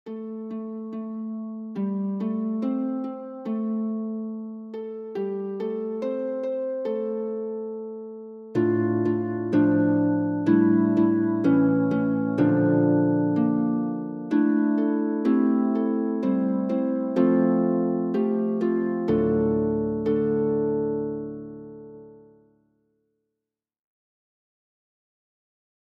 SATB (4 gemischter Chor Stimmen) ; Partitur.
Genre-Stil-Form: geistlich ; Zuruf
Tonart(en): F-Dur